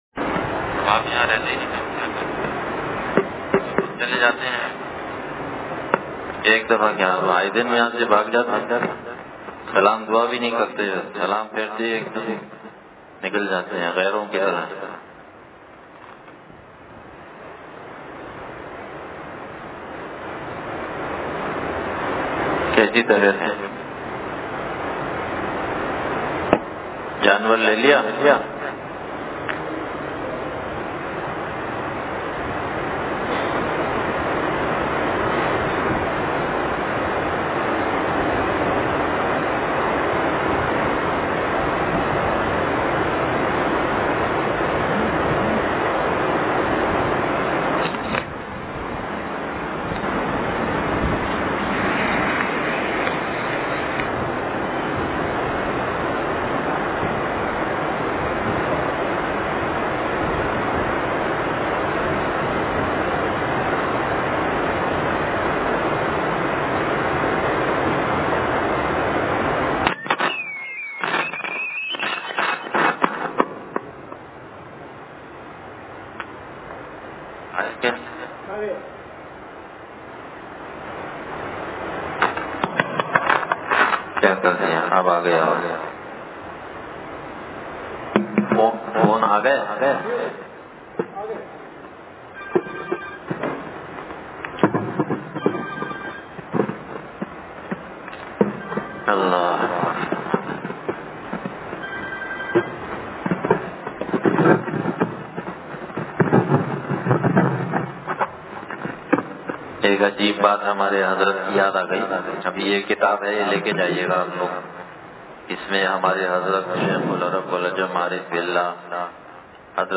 بیان – انصاریہ مسجد لانڈھی